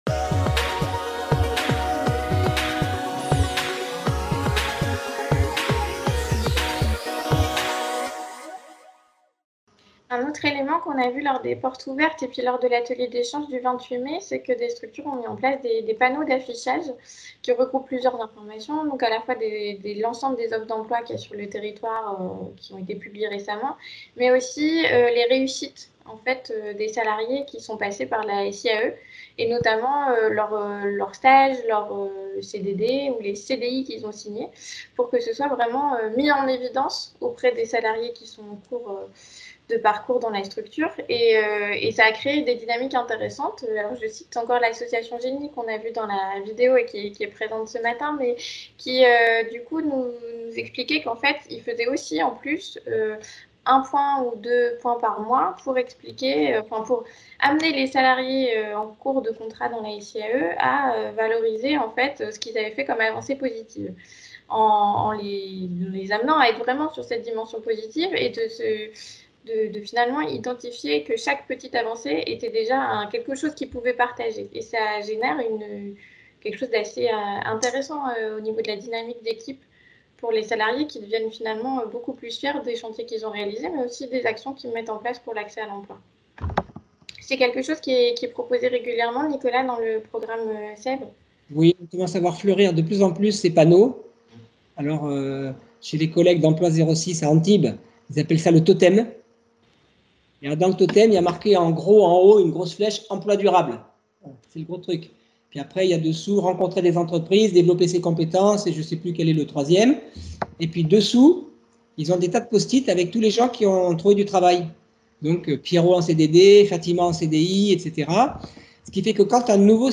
Vous pouvez à présent accéder à cette conférence sous forme de podcast en trois épisodes.